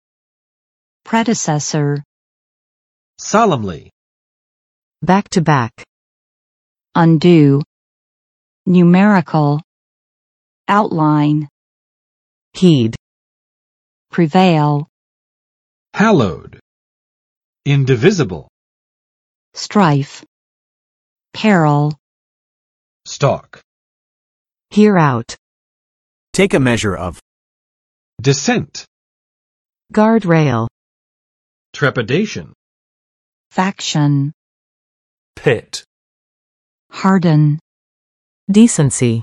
Vocabulary Test - January 21, 2021
[ˋprɛdɪ͵sɛsɚ] n. 前任
predecessor.mp3